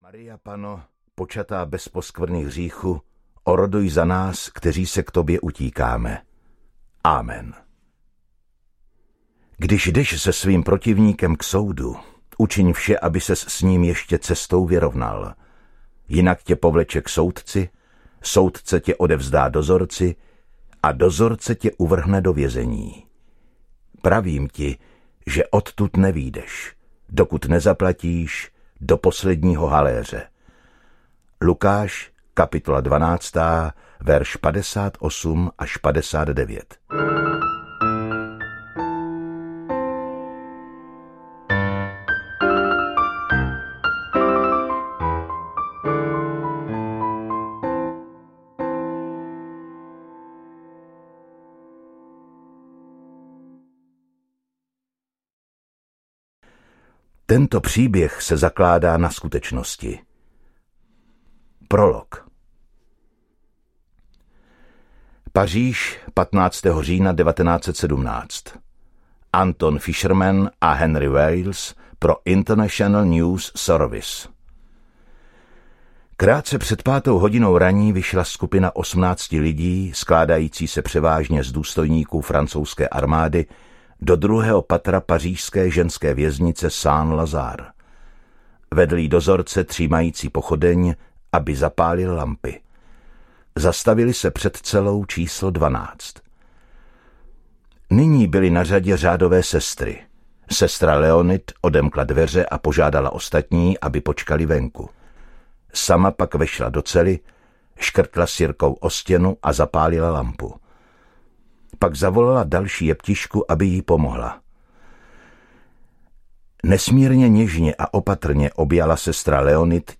Vyzvědačka audiokniha
Ukázka z knihy